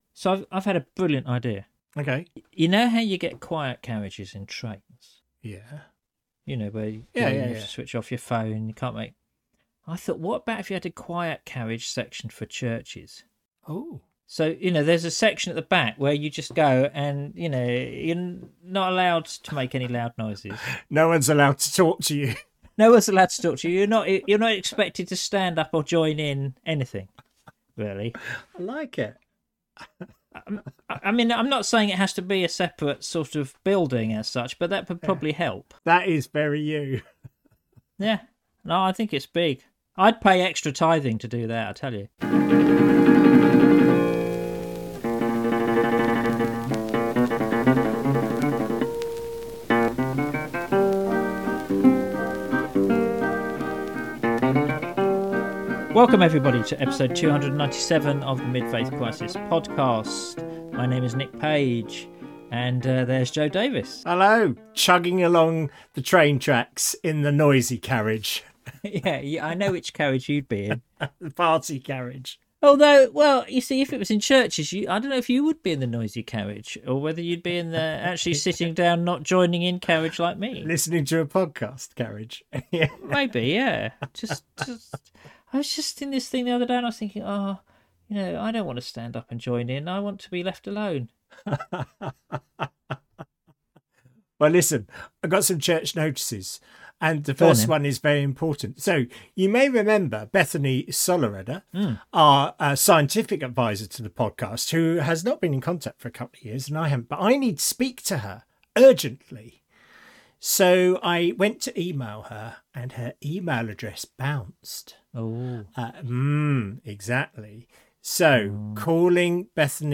Episode 297: Jesus was not an accountant: an interview